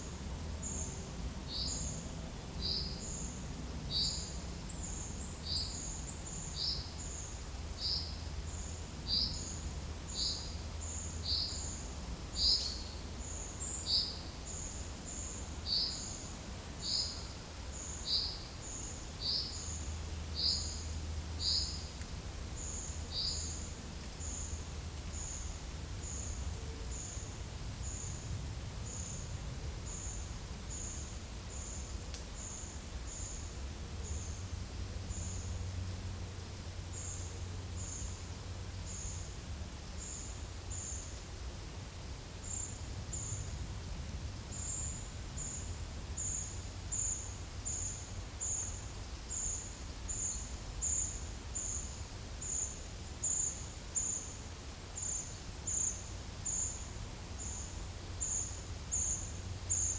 Monitor PAM
Certhia familiaris
Certhia brachydactyla
Turdus iliacus
Fringilla coelebs